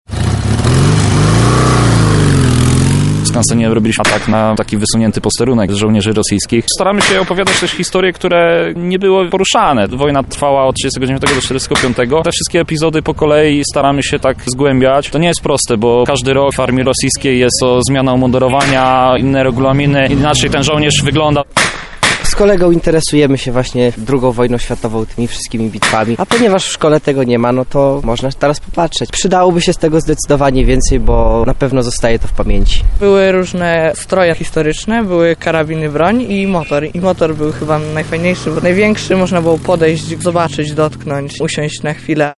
W sobotę Muzeum Wsi Lubelskiej zamieniło się w miejsce bitwy na przedpolach stolicy ZSRR.
rekonstrukcja-bitwy-pod-Moskwą.mp3-1.mp3